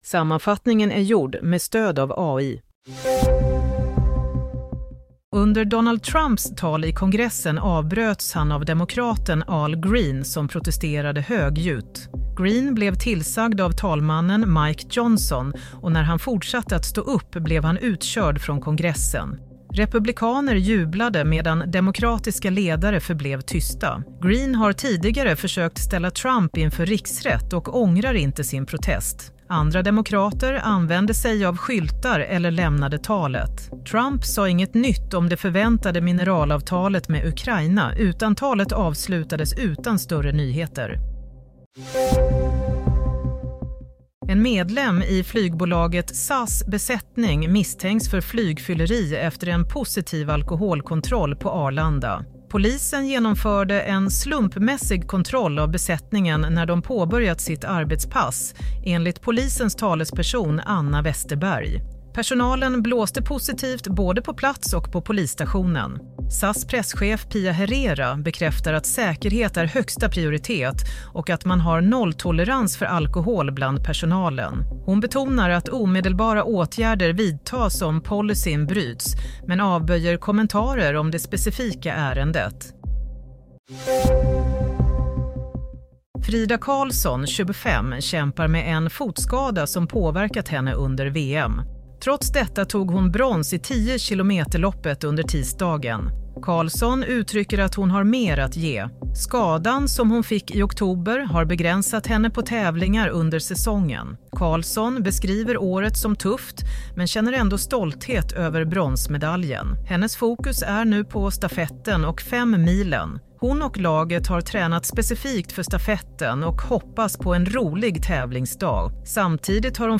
Nyhetssammanfattning - 5 mars 07:00
Sammanfattningen av följande nyheter är gjord med stöd av AI.